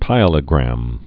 (pīə-lə-grăm)